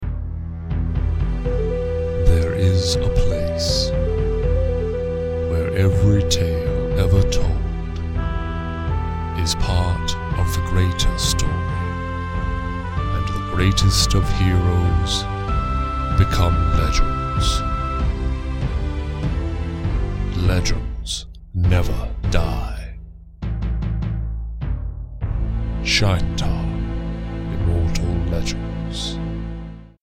Shaintar Radio Ad
Script, Vocals, and Musical Score: